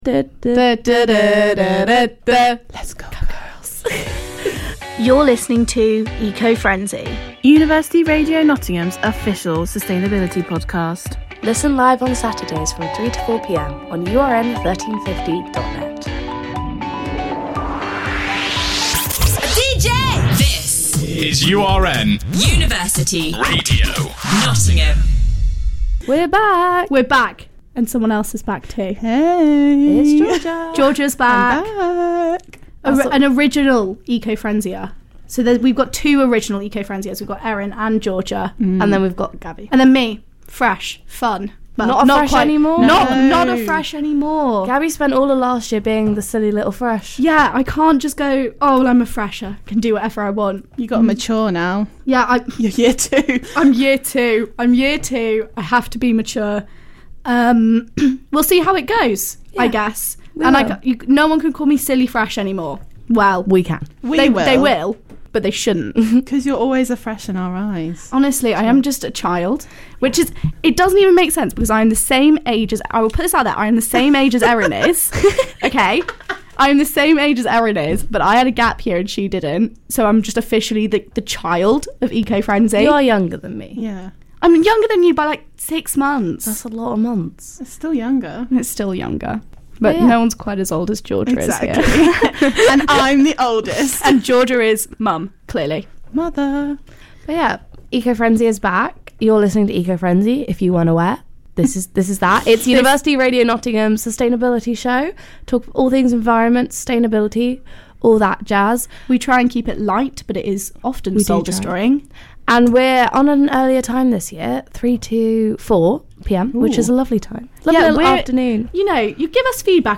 Don't worry there is plenty of environment content as they take time to discuss the environmental issues everyone noticed over the holidays. ~ Originally broadcast live on University Radio Nottingham on Saturday 8th October 2022